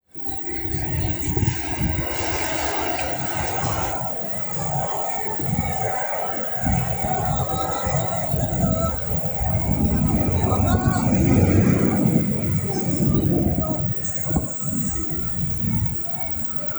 マイク等を持っていないので、録音状態はそれほど良いとは言えないけど、記録しておこう。
確認したところで、西武渋谷前まで行くと先ほどよりさらに音がデカい。
そして最後に西武渋谷前は、20kHzの帯域に真横にハッキリとした太い線が出現した。先ほどの2カ所とは比べ物にならないほど大きな音が鳴っていたことがわかる。
これは、西武渋谷の2つの建物の間を、音が反響し増幅して音が大きくなっている可能性がある。
▼西武渋谷前の音
西武渋谷前.wav